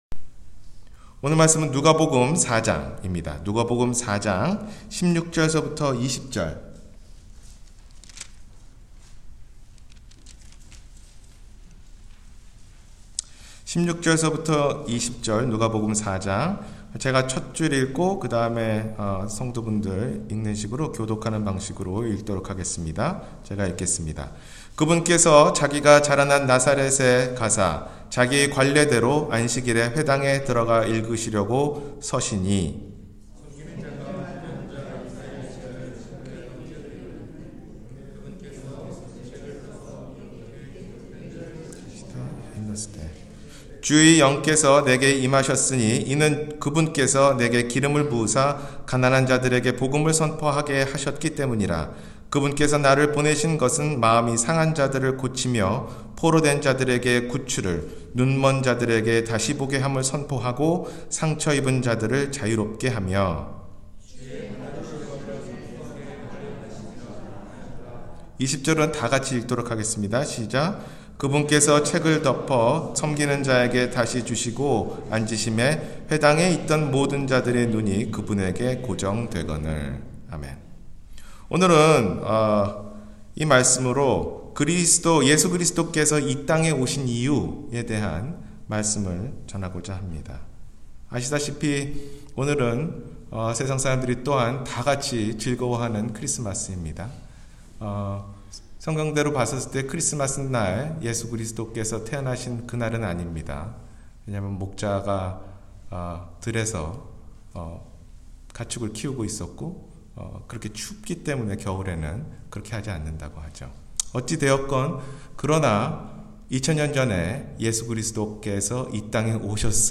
예수 그리스도께서 이 땅에 오신 이유-주일설교